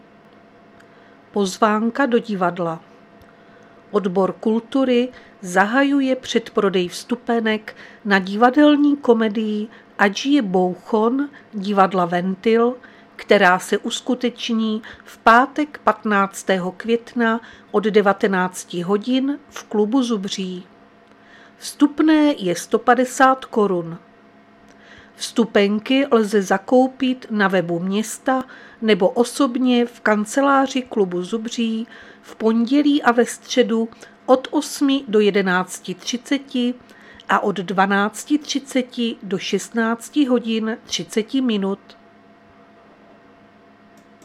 Záznam hlášení místního rozhlasu 10.2.2026
Zařazení: Rozhlas